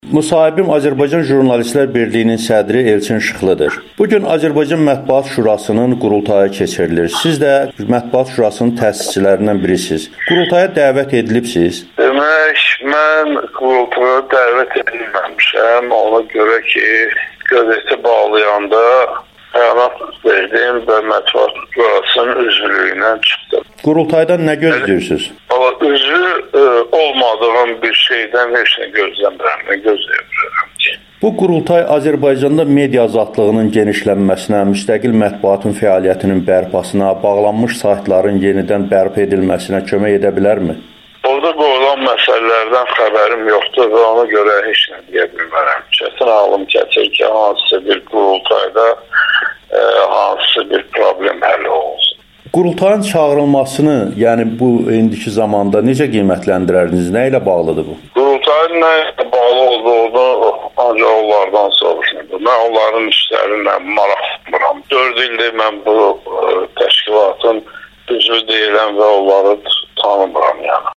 Azərbaycan jurnalistlərinin qurultayı keçirilir (Müxtəlif baxışlar) [Video, audio müsahibələr]